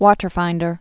(wôtər-fīndər, wŏtər-)